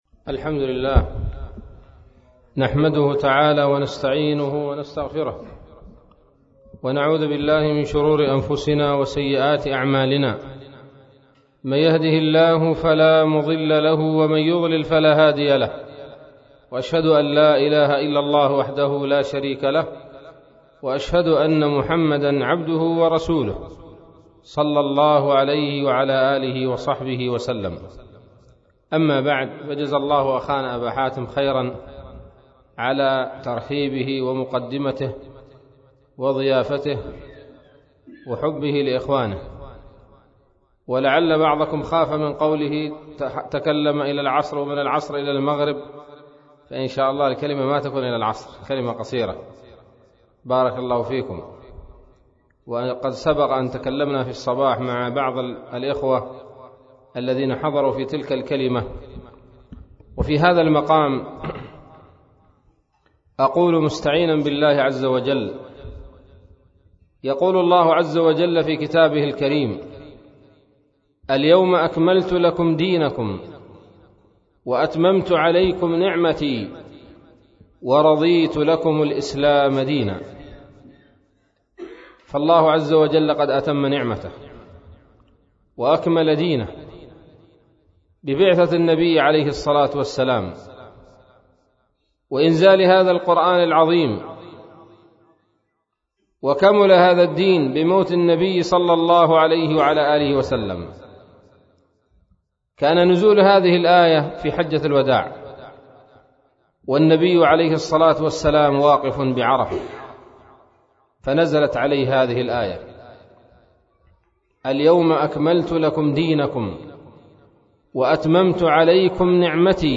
محاضرة بعنوان :((لا تضيّع عمرك!